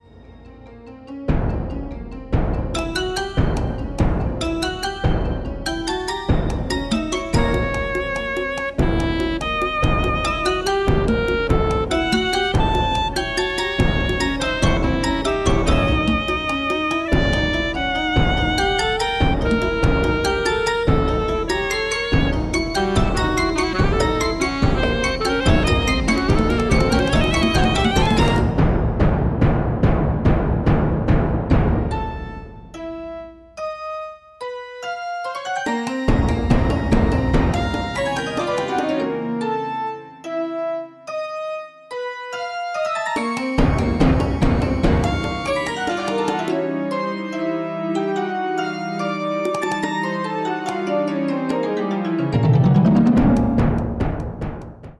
saxophone
piano
computer performer